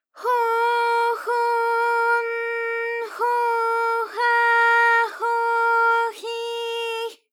ALYS-DB-001-JPN - First Japanese UTAU vocal library of ALYS.
ho_ho_n_ho_ha_ho_hi_h.wav